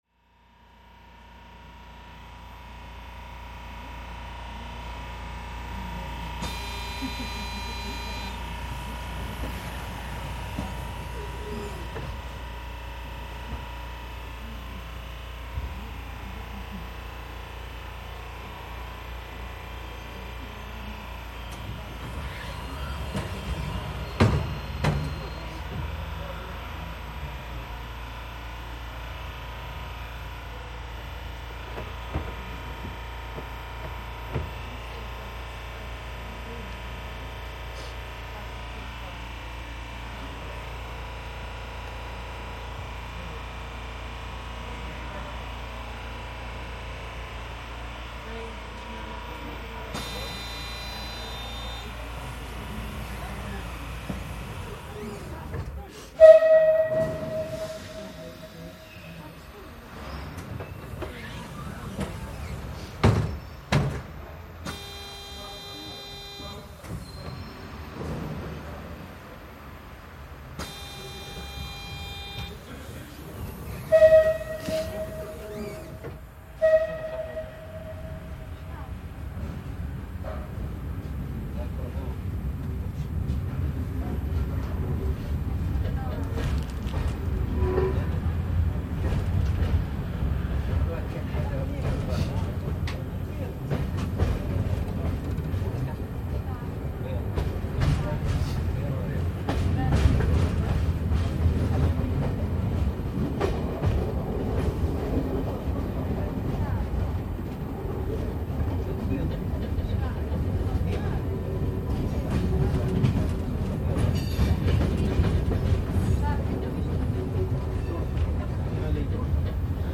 Due to the high unemployment rate in Tunisia, both Tunisians from various regions and refugees depend on slow trains for their daily commute to Tunis. The sluggish pace of these trains adds an extra layer of challenge to their journeys, as evident in the accompanying recording. Many individuals undertake a daily trip to Sidi Bou Said, aspiring to secure employment in this artistic and laid-back seaside village often referred to as the "Tunisian Santorini."
Part of the Migration Sounds project, the world’s first collection of the sounds of human migration.